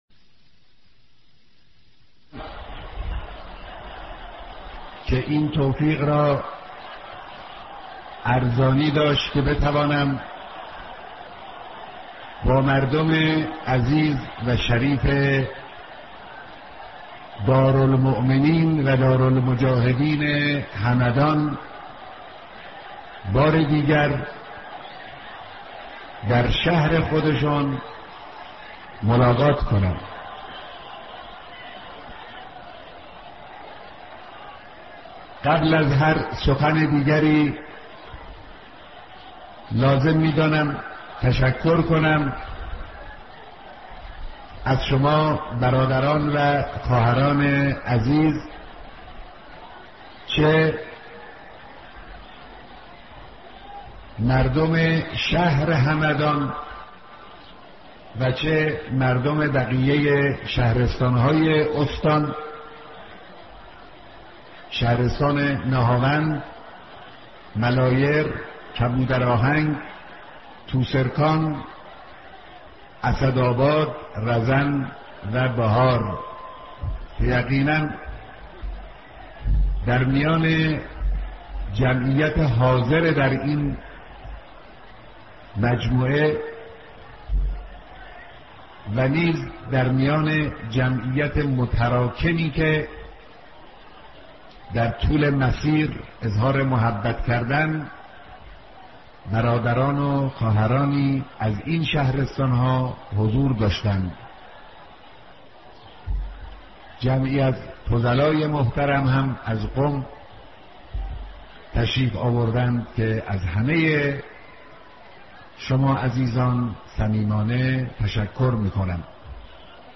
بيانات رهبر معظم انقلاب اسلامى در اجتماع بزرگ مردم همدان
سخنرانی